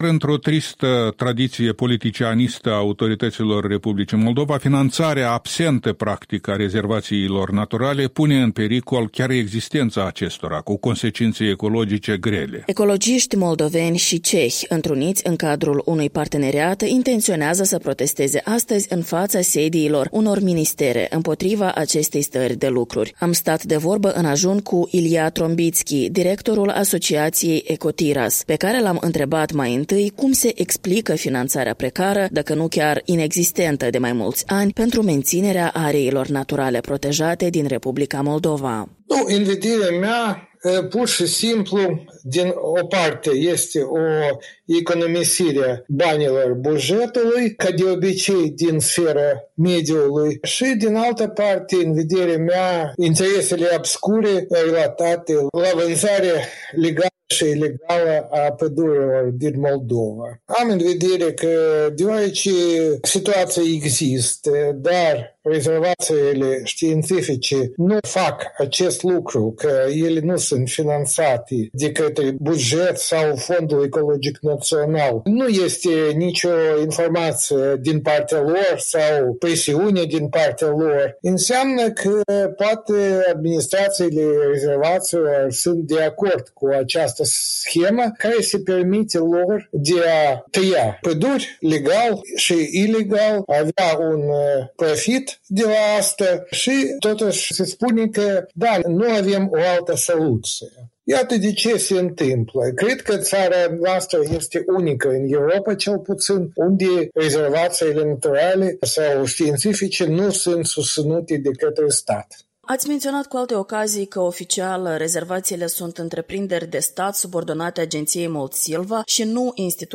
Interviu matinal